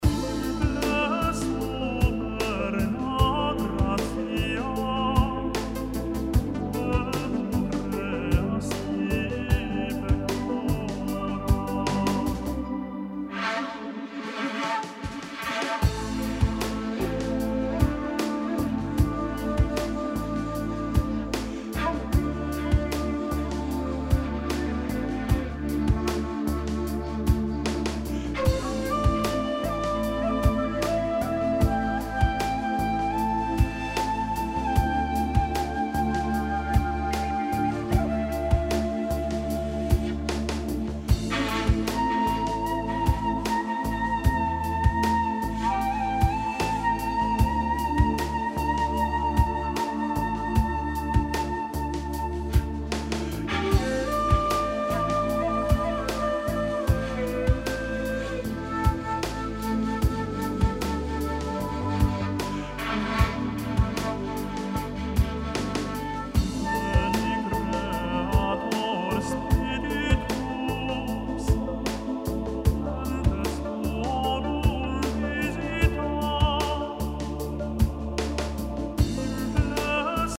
Sodele, jetzt habe ich nochmals daran gearbeitet, habe die Sprünge händisch angeglichen und die Atmer weitgehend sehr leise gestellt. Anderer Hall, nettes Modulationsdelay und ein paar Panoramaeffekte, damit der geneigte Hörer aufweicht.
Die längeren Anblasphasen musste ich einfach drin lassen, weil sowas ja kein anderes Instrument hat.